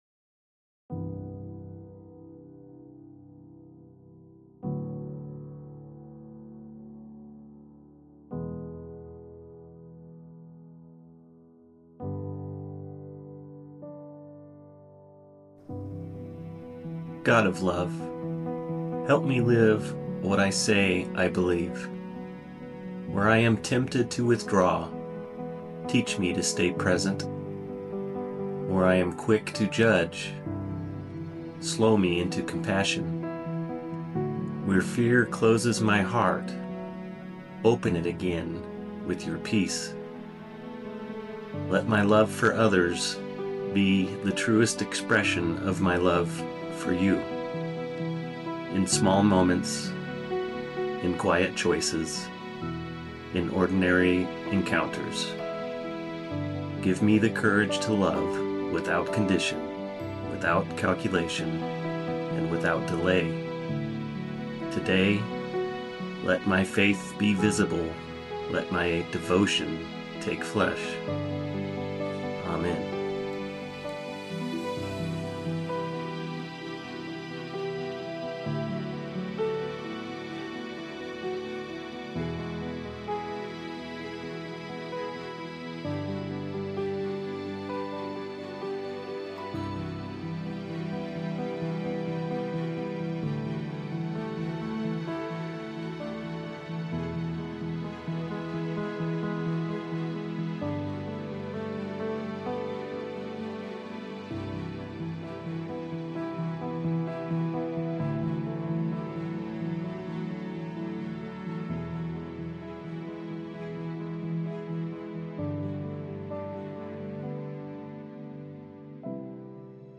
🎧 A Spoken Blessing